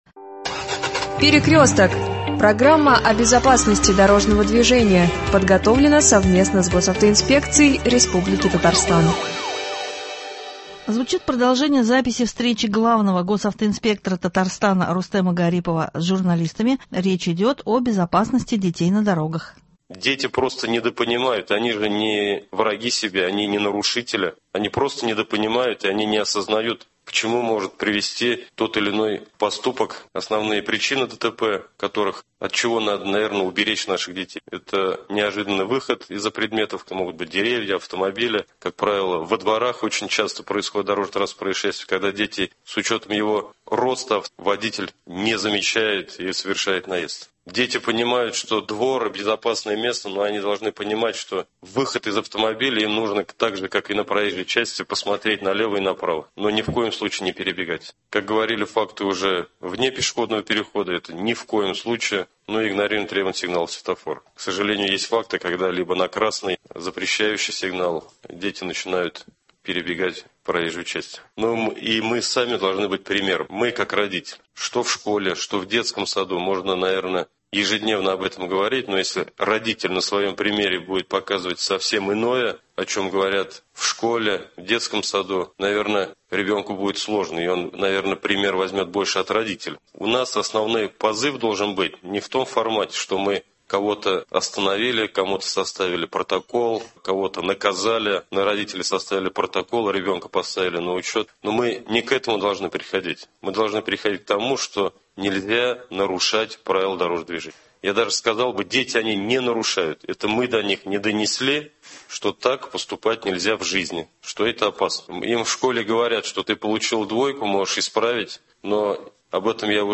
Звучит продолжение записи встречи главного госавтоинспектора Татарстана Рустема Гарипова с журналистами , речь идет о безопасности детей на дорогах.